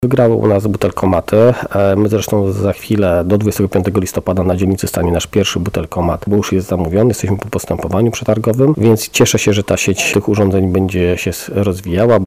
Burmistrz Bielan Grzegorz Pietruczuk zapewnia, że pierwszy butelkomat stanie najpóźniej do 25 listopada.
burmistrz1.mp3